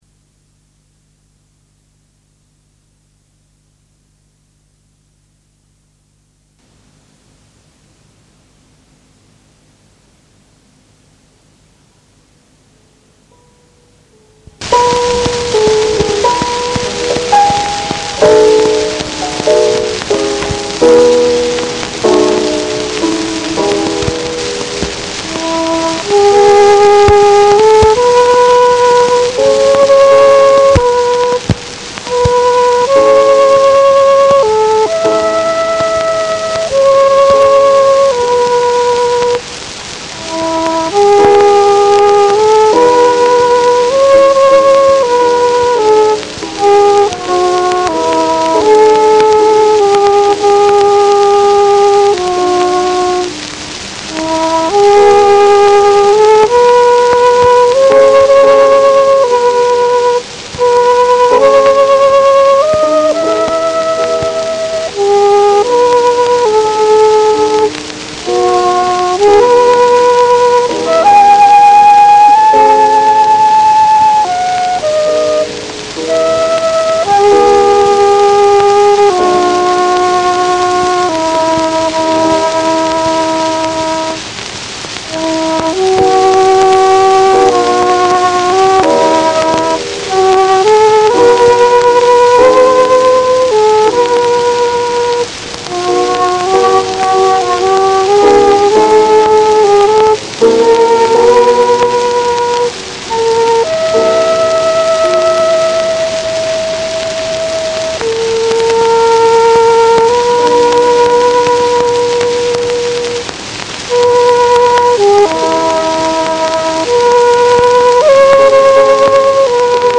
Flautists of the Hallé Orchestra
Of his playing, another writer was to note that – “in his hands the flute became articulate…….it literally sings, especially on the lower register”. This can still be ascertained today, to some extent, when listening to his 1904 recording of “Auld Robin Gray”, where his ringing tone, firmly centred, shines through the surface noise of the cylinder.